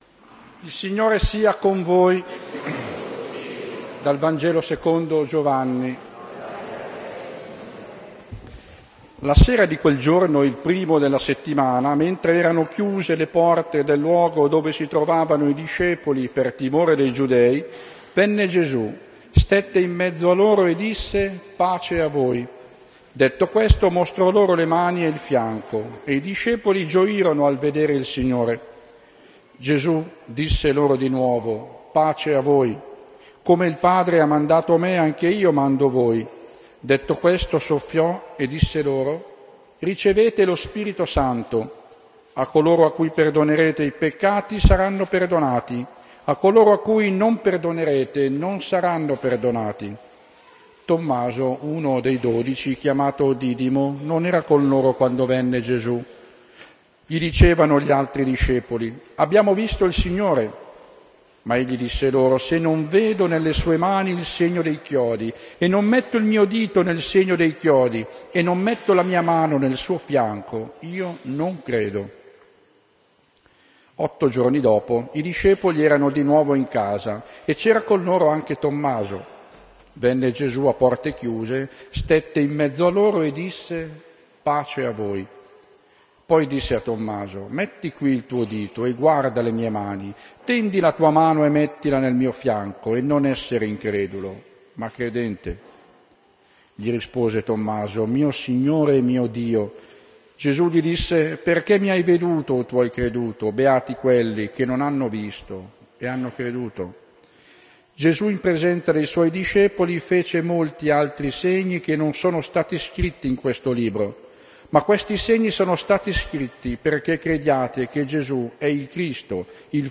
S.MESSA RADUNO GIOVANI 1 maggio 2011 – AUDIO
10-Vangelo.ogg